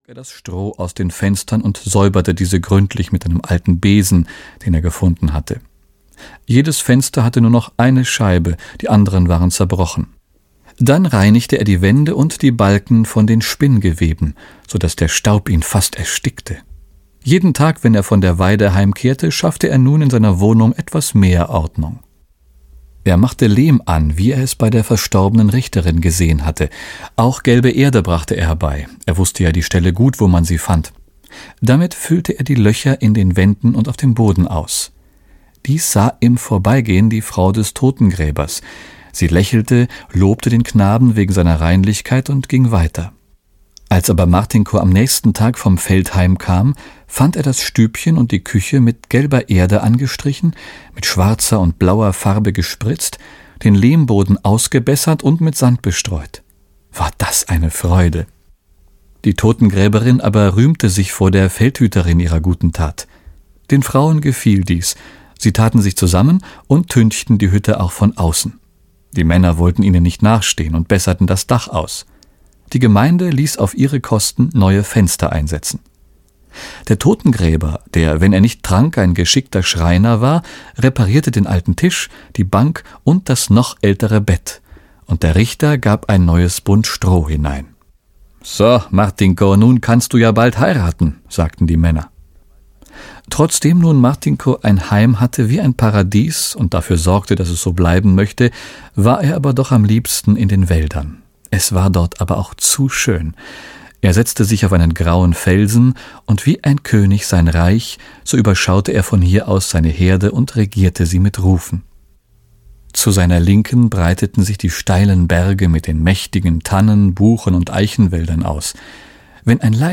Ohne Gott in der Welt - Kristina Roy - Hörbuch